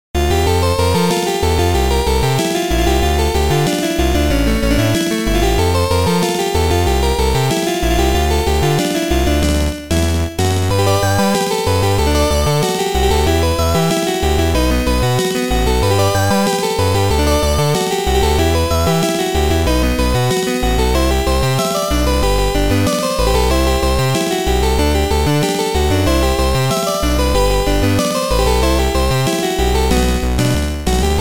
Sound Format: Noisetracker/Protracker
Sound Style: Chip / Sorrow